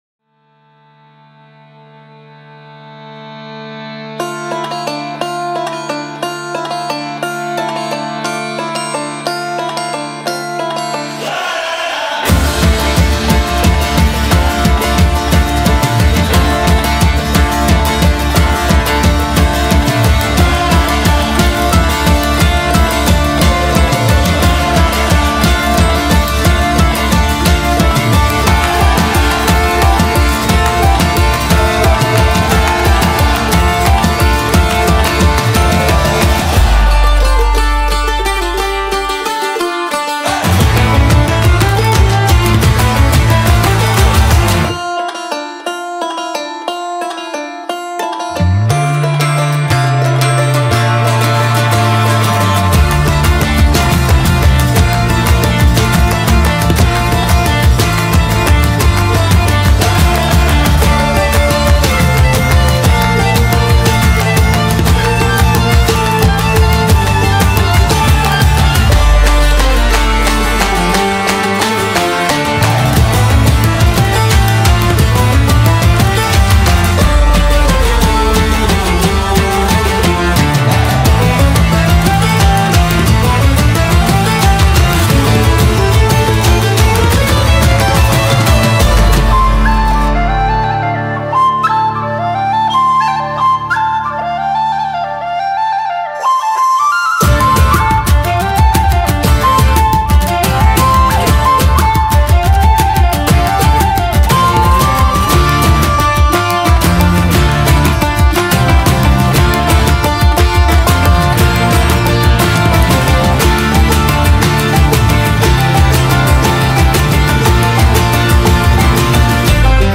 Instrumental: